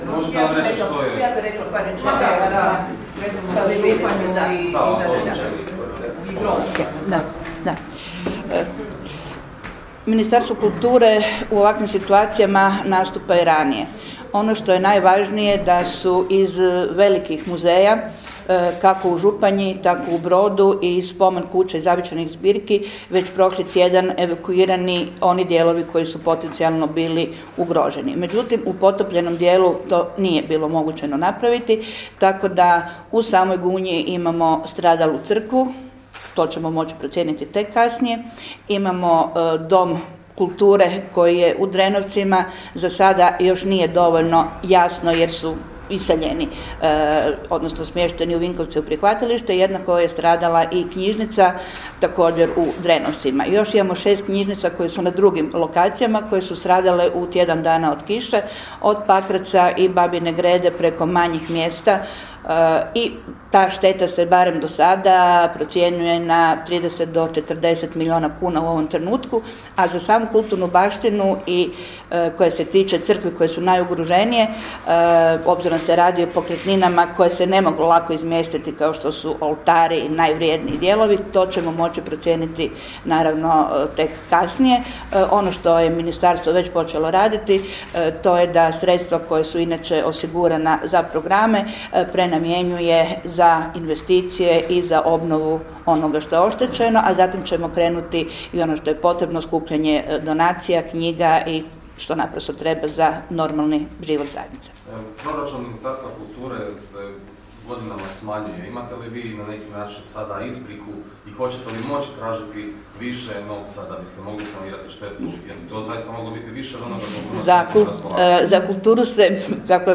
>>>  Izjava ministrice kulture .mp3